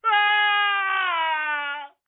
Sound Effects
Funny Scream Sound